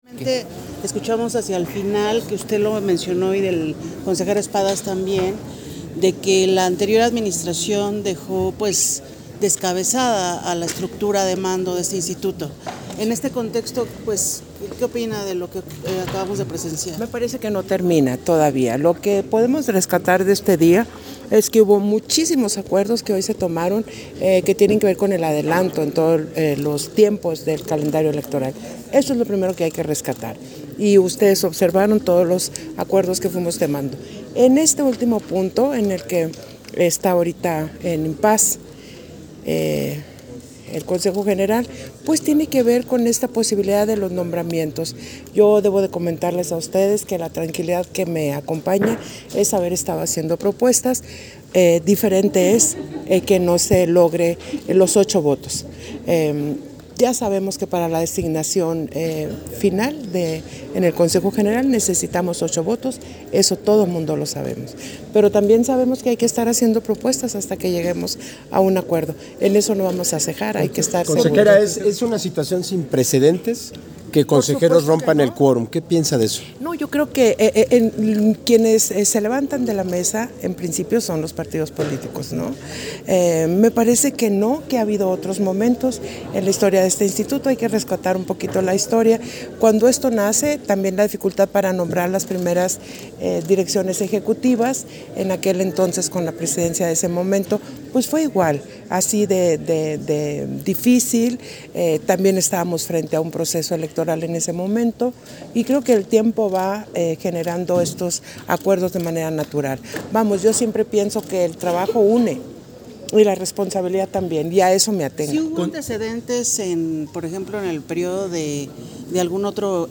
151223_AUDIO_ENTREVISTA-CONSEJERA-PDTA.-TADDEI-SESIÓN-ORD
Versión estenográfica de la entrevista que la Consejera Presidenta, Guadalupe Taddei, concedió a diversos medios de comunicación, en la Sesión Ordinaria del Consejo General